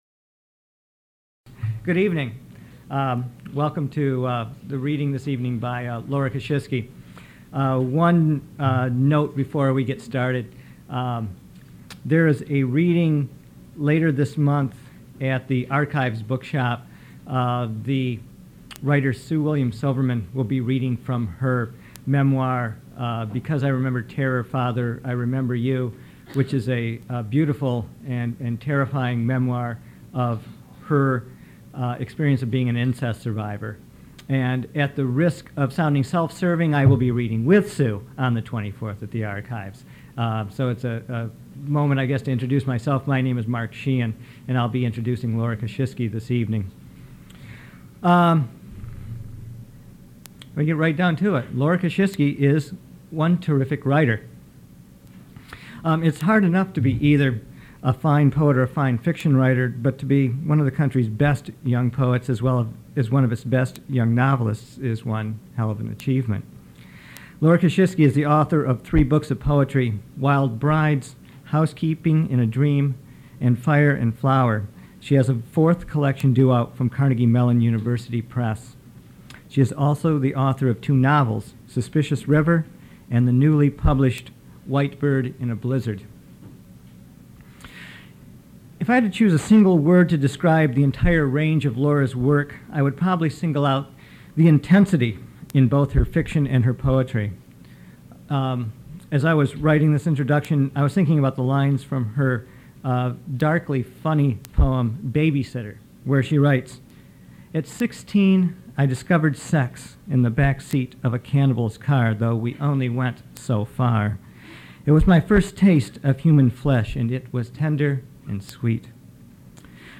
Poet and novelist Laura Kasischke reads her selected works at the Michigan Writers Series